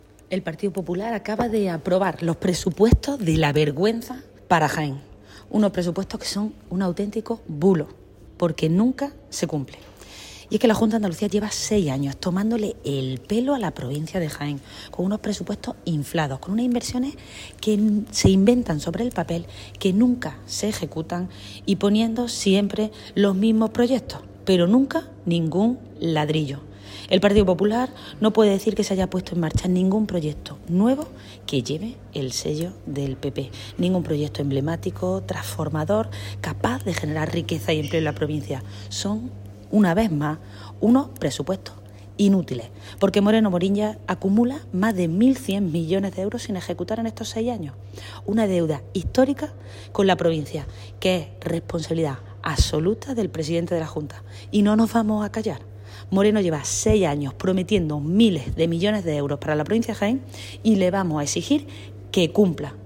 Cortes de sonido